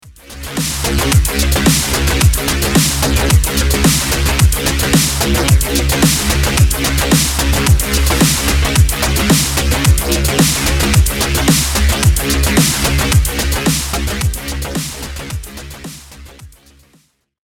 If we now listen back to our example track you will notice how much more energy we’ve added to the once dreary melody, and how huge it sounds in comparison!
DDD_Synth_Wet.mp3